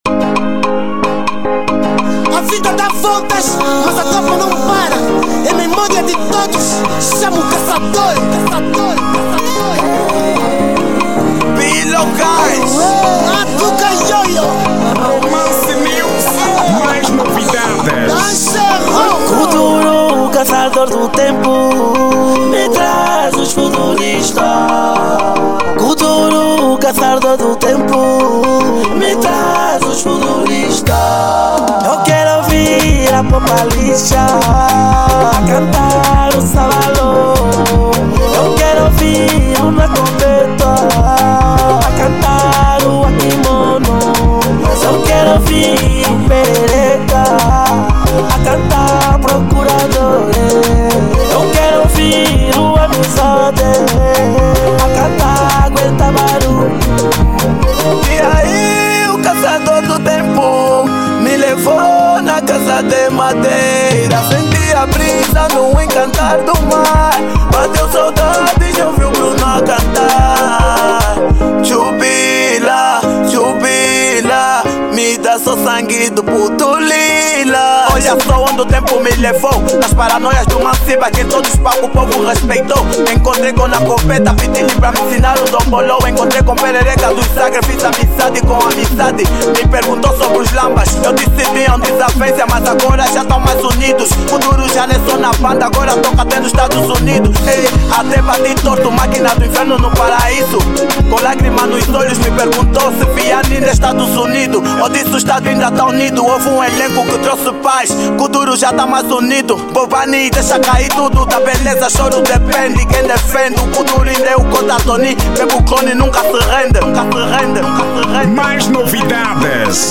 Estilo: Kuduro